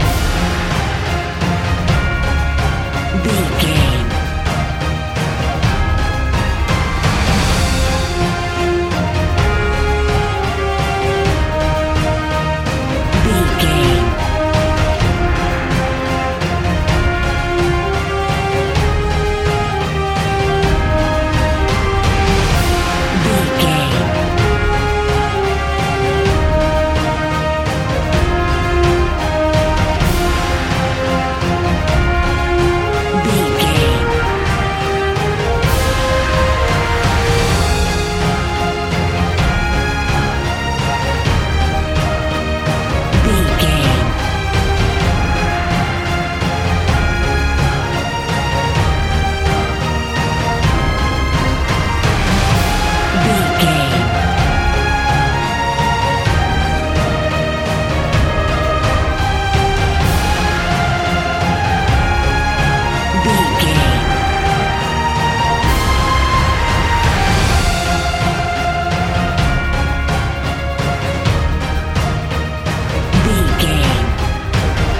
Epic / Action
Fast paced
Aeolian/Minor
Fast
heavy
hybrid
brass
drums
strings
synthesizers